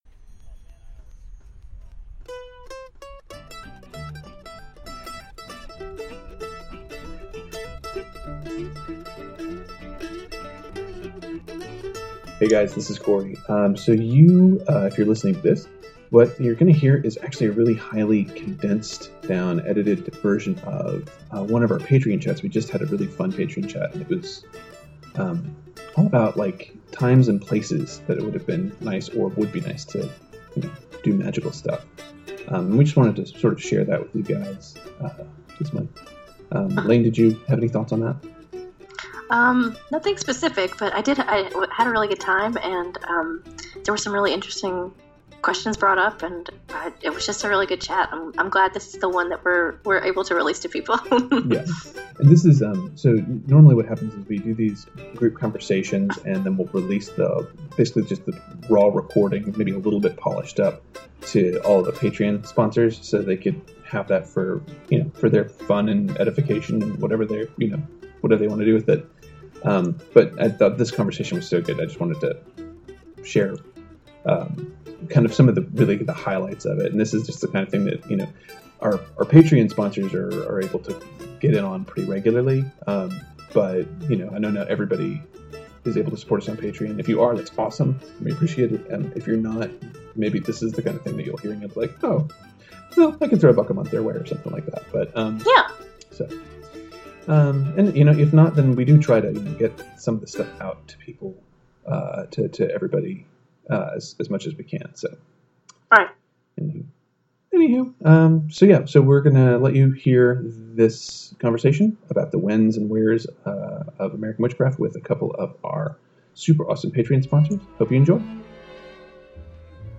Summary: This episode is a special condensed version of one of our bi-monthly Patreon group discussions.